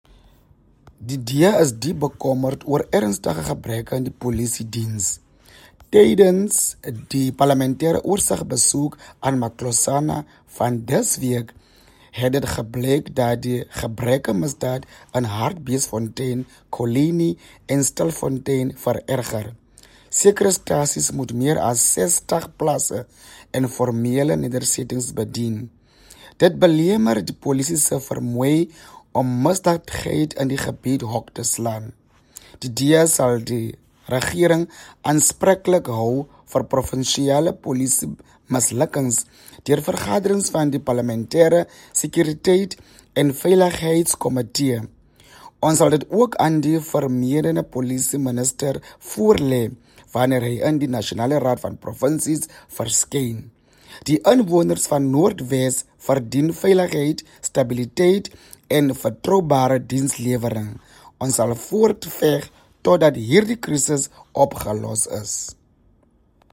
Issued by Ofentse Mokae MP – DA Member of Select Committee on Security and Justice
Afrikaans soundbites as well as video by Ofentse Mokae MP.